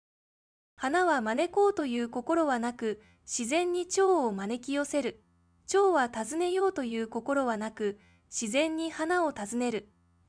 AI音声生成サービス
▶ナレーションを聴く 利用サービス： CoeFont ※10秒ほどの音声が流れます 冗談はさておき技術の進歩のおかげです。